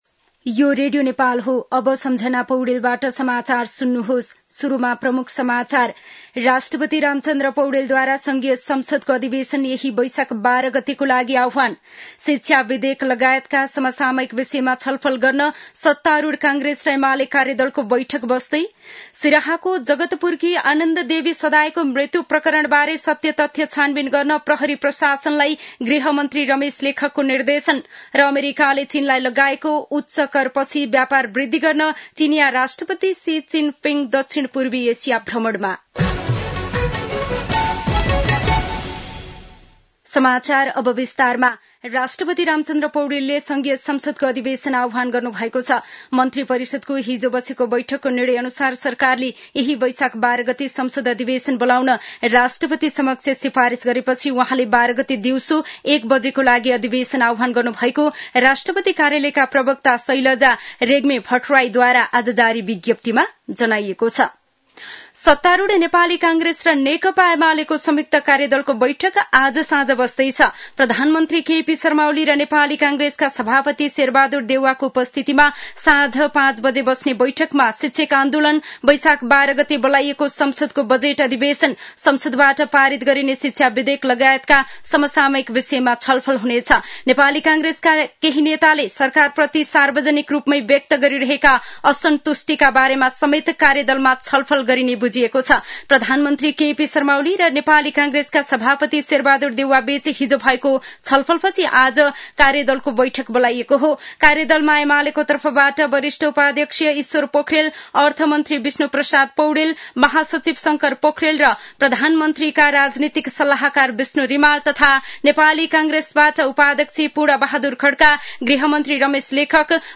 दिउँसो ३ बजेको नेपाली समाचार : ३ वैशाख , २०८२